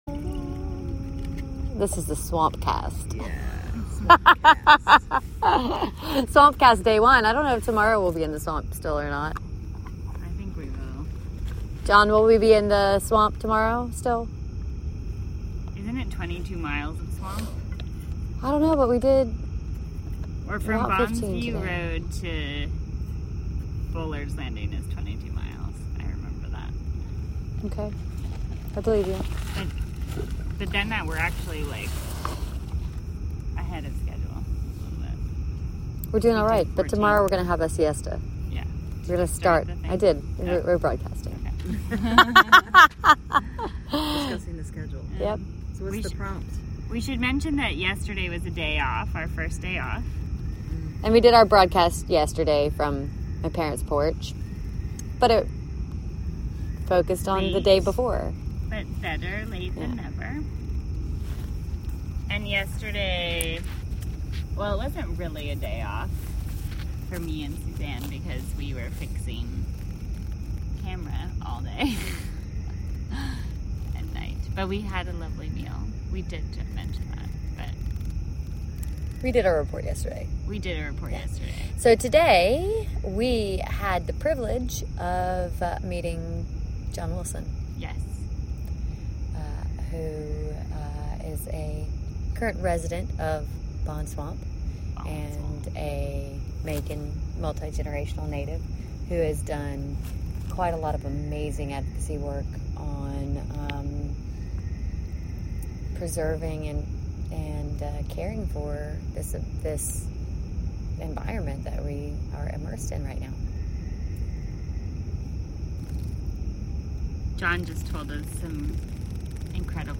Live from Flux Projects: Atlanta to the Atlantic, day 12 (Audio)